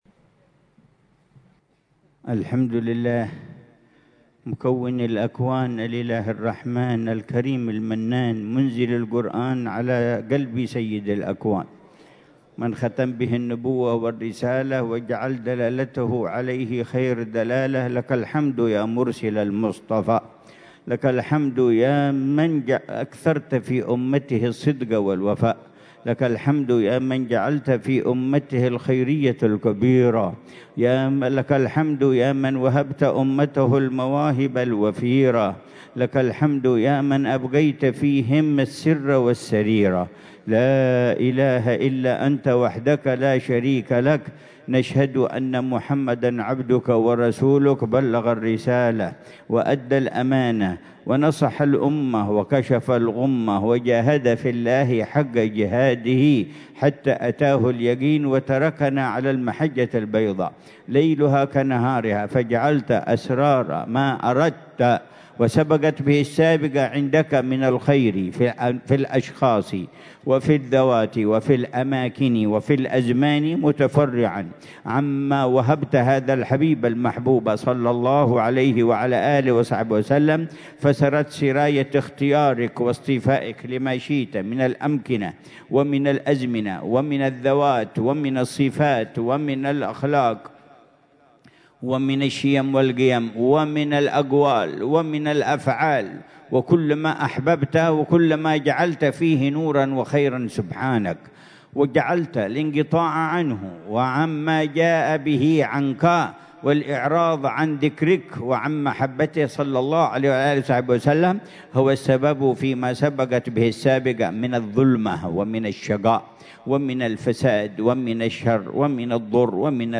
محاضرة العلامة الحبيب عمر بن محمد بن حفيظ ضمن سلسلة إرشادات السلوك ليلة الجمعة 9 جمادى الأولى 1447هـ في دار المصطفى، بعنوان: